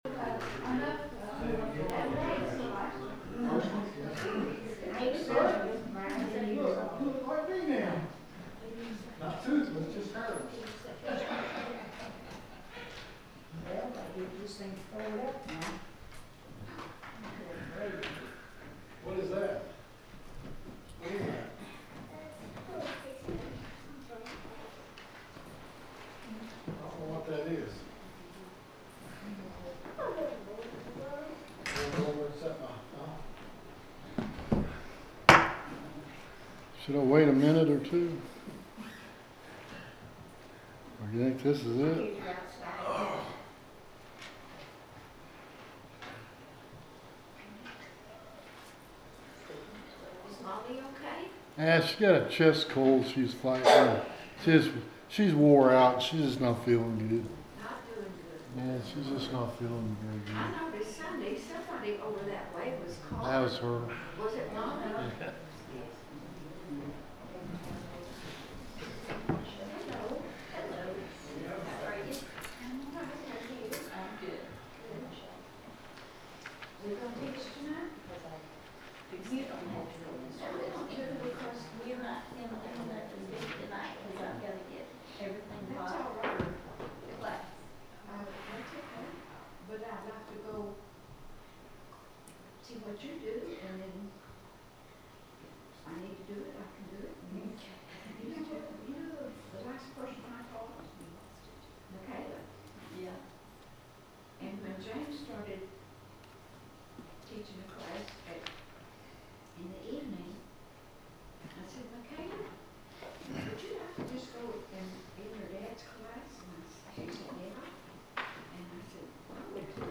The sermon is from our live stream on 2/25/2026